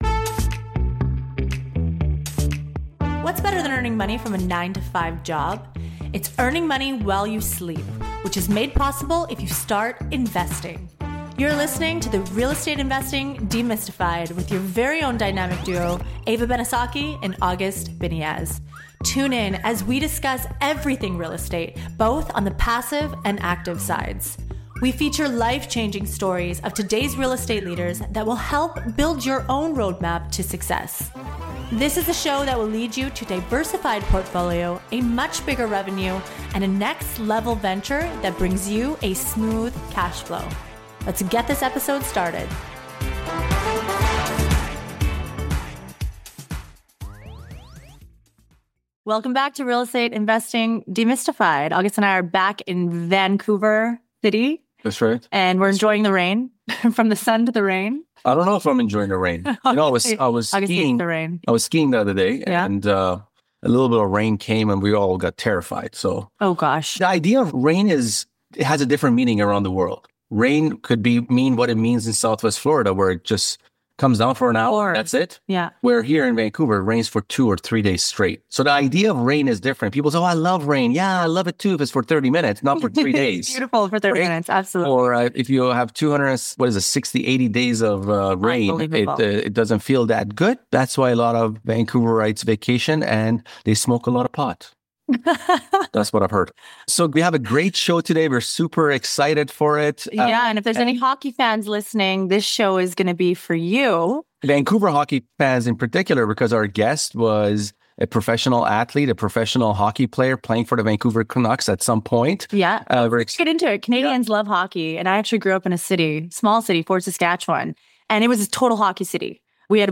Eddie Lack, a former professional hockey player, shares his business model, strategies, and approaches as a luxury home developer in Arizona.